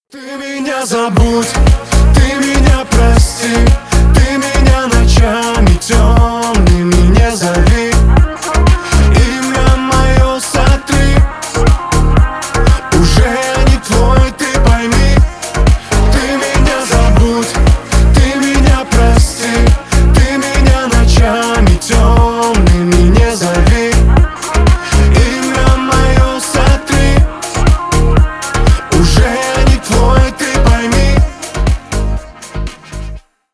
• Качество: 320, Stereo
мужской вокал
Club House
Cover